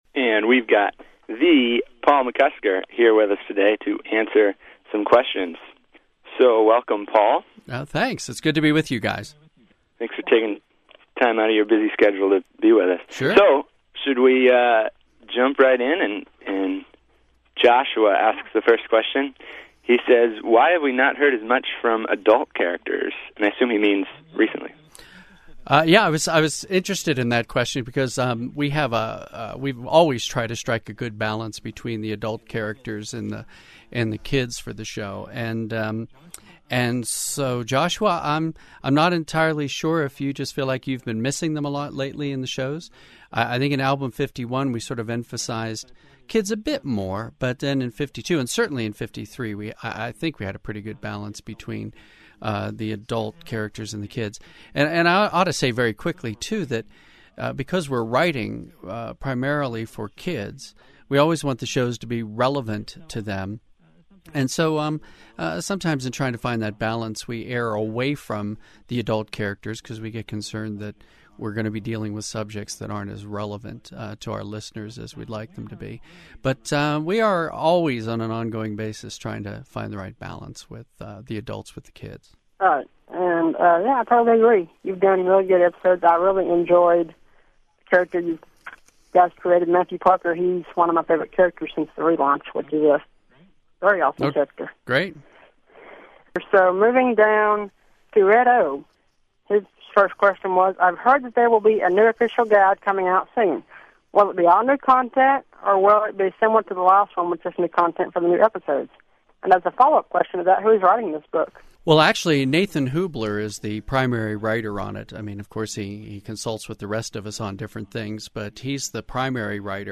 "The Odyssey Scoop" - Audio Interview